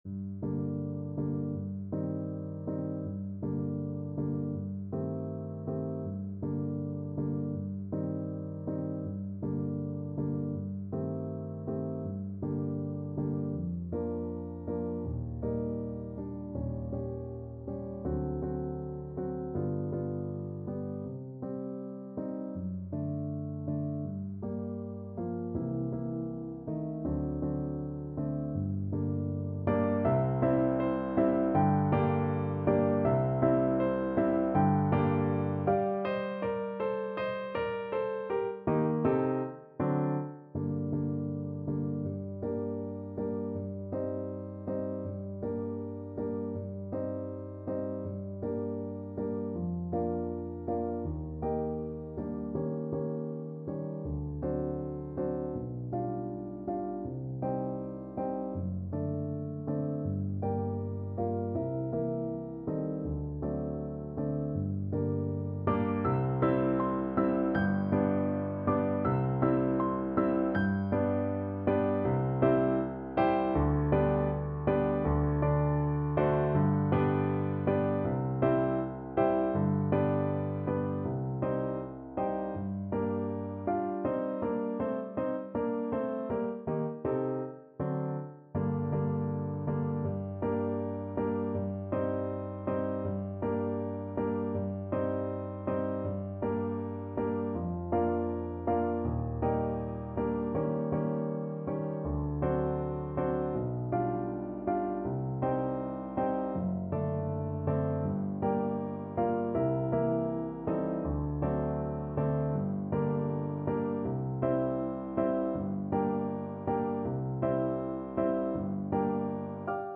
~ = 100 Andante
2/4 (View more 2/4 Music)
Classical (View more Classical Clarinet Music)